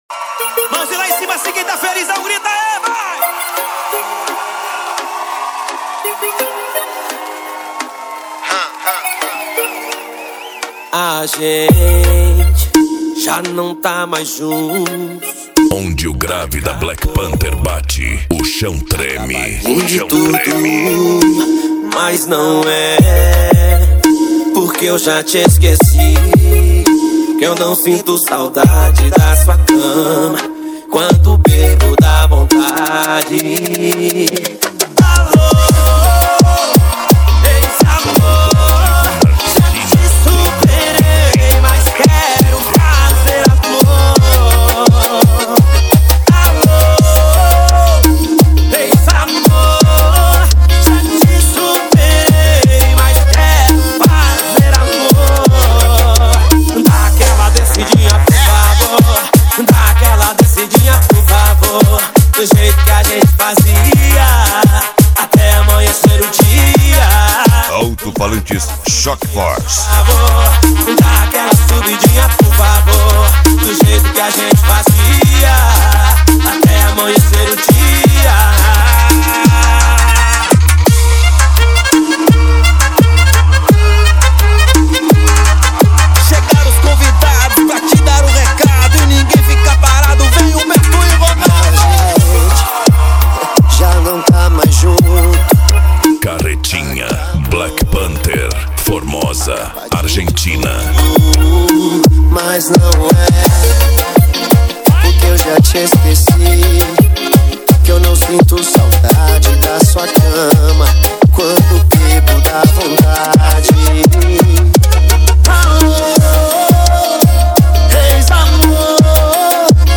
Bass
Funk
Psy Trance
Remix